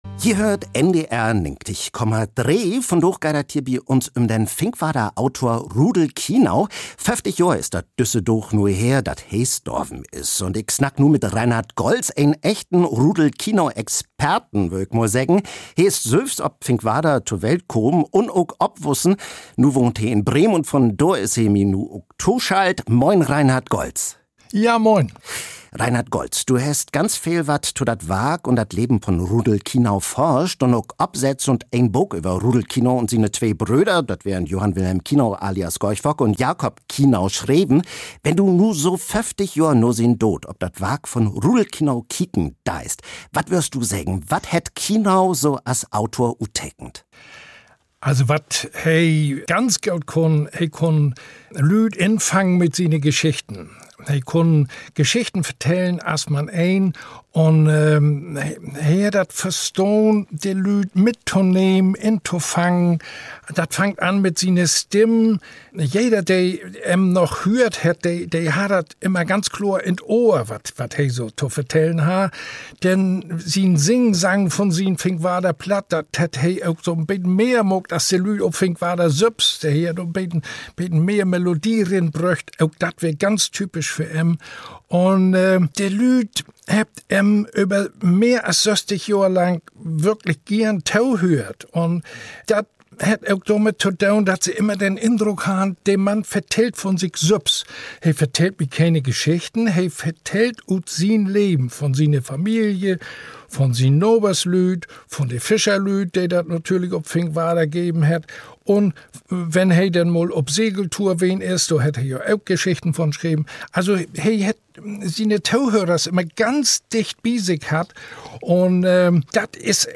In een besünnere Sendung hett sück ok dat Radio op düssen Dichtersmann besonnen.